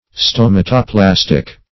Meaning of stomatoplastic. stomatoplastic synonyms, pronunciation, spelling and more from Free Dictionary.